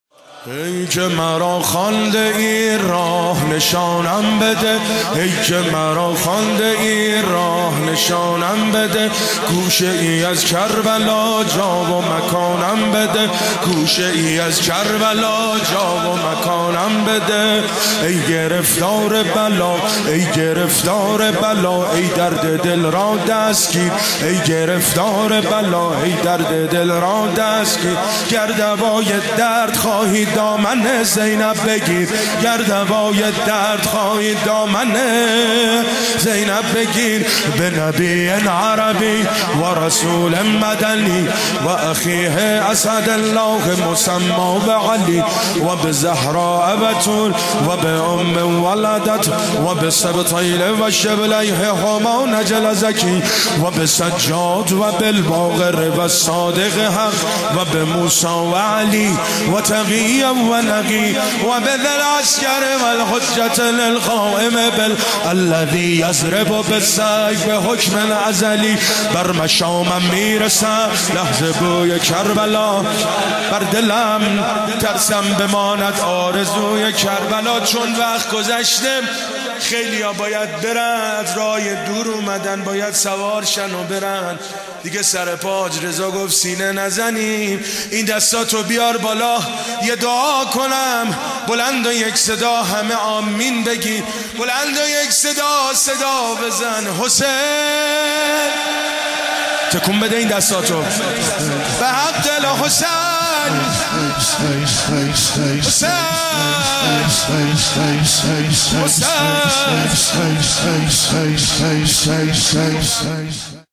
پانزدهمین اجتماع مدافعان حرم در مهدیه تهران